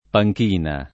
panchina [ pa j k & na ] s. f.